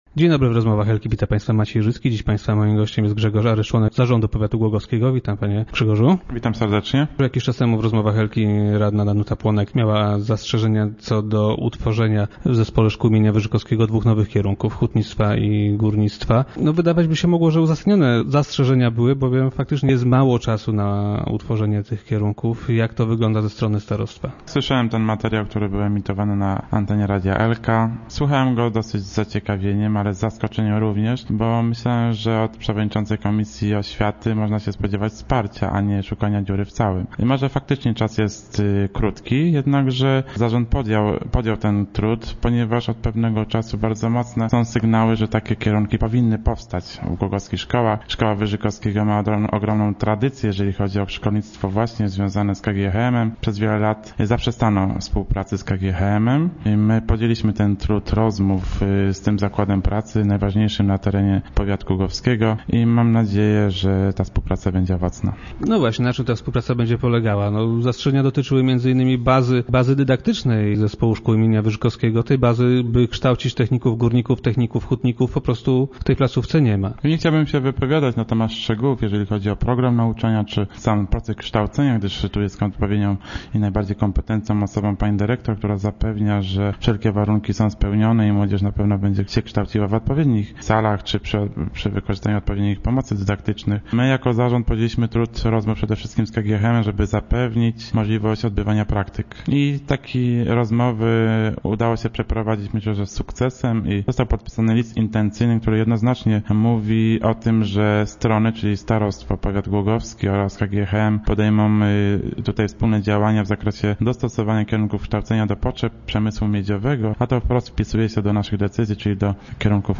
- Rozmowy z radną Płonek wysłuchałem z zainteresowaniem, ale też i z zaskoczeniem. Wydawać by się mogło, że od przewodniczącej komisji oświaty w radzie powiatu głogowskiego, należy oczekiwać wsparcia, a nie szukania dziury w całym - powiedział na radiowej antenie Aryż.